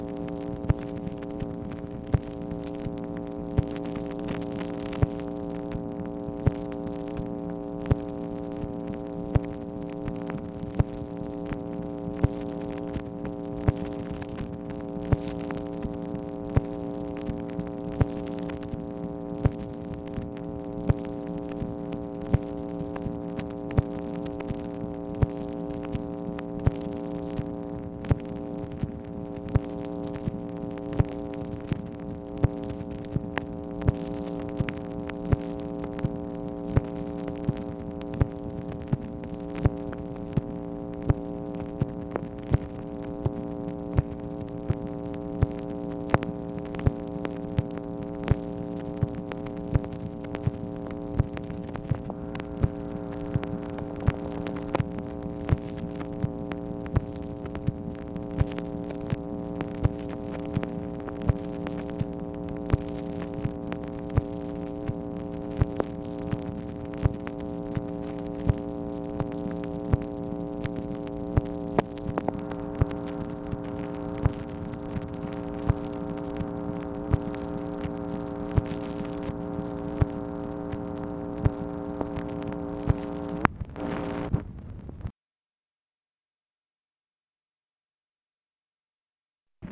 MACHINE NOISE, May 26, 1965